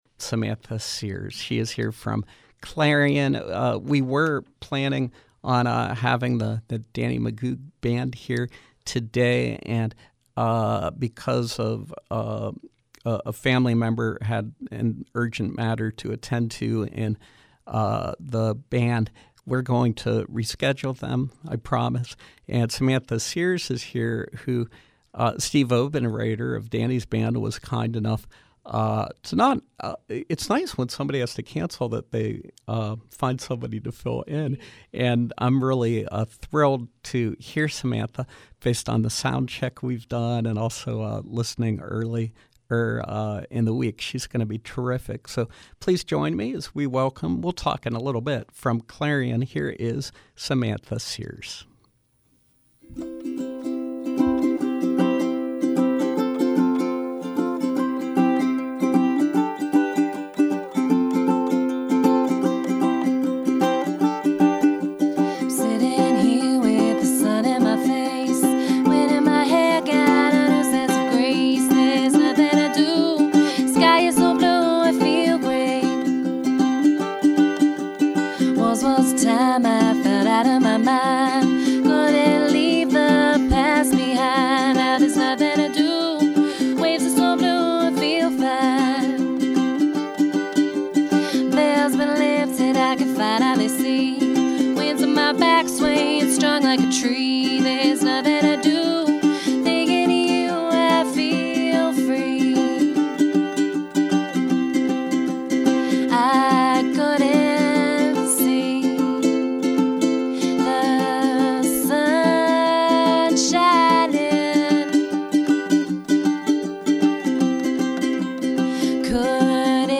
Live music with Clarion-based singer/songwriter